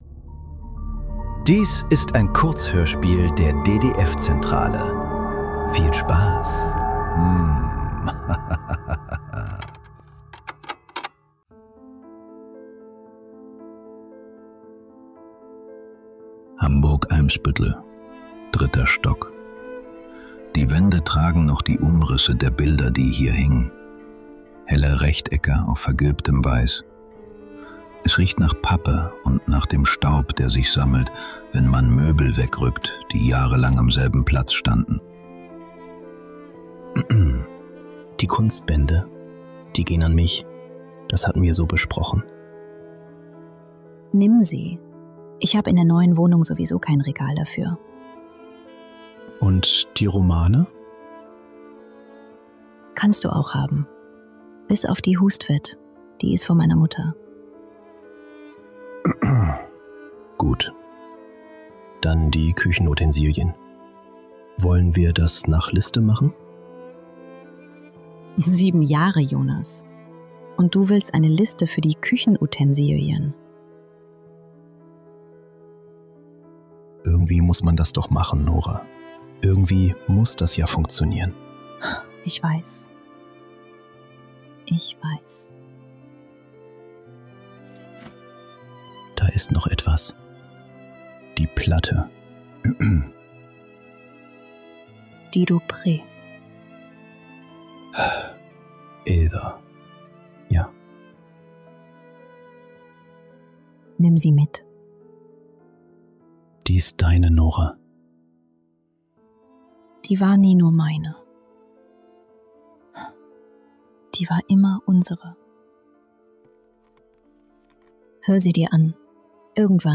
Bis zum Ende ~ Nachklang. Kurzhörspiele. Leise.